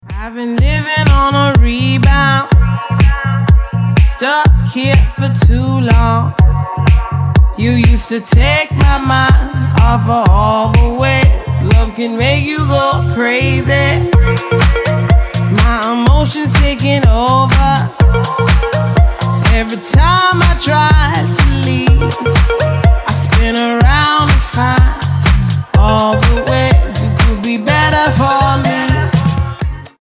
dance
club
house